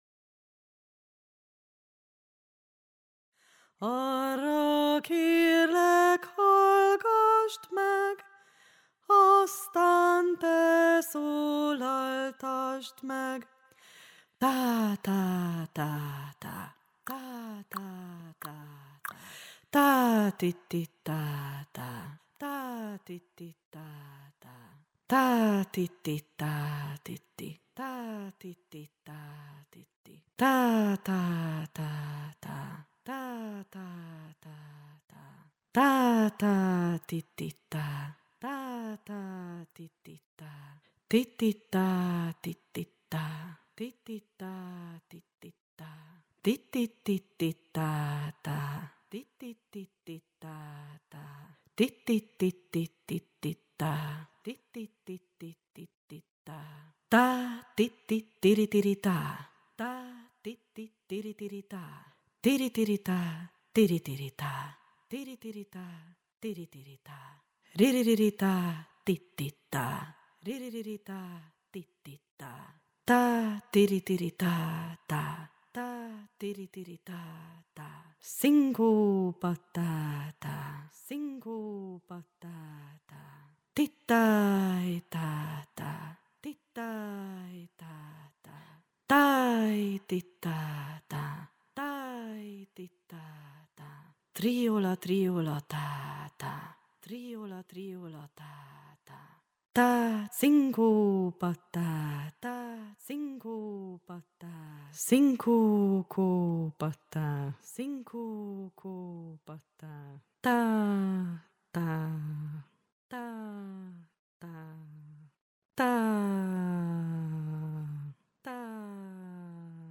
RITMUS JÁTÉK most kezdők előképzősök és első osztályosok számára